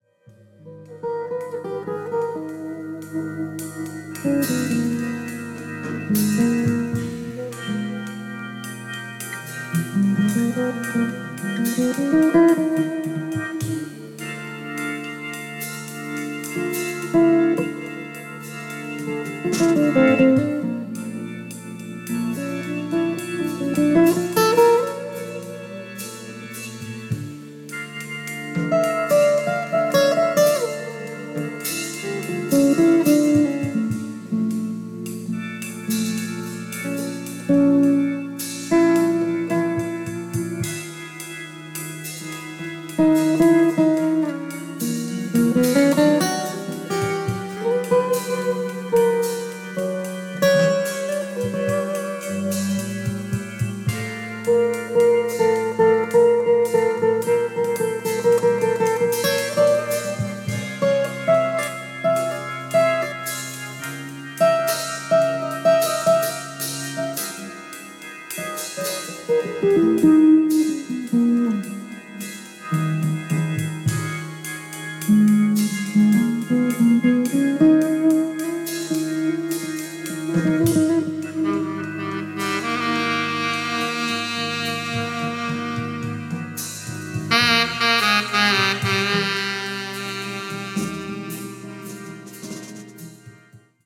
Drums
Organ, Piano
Guitar
Saxophone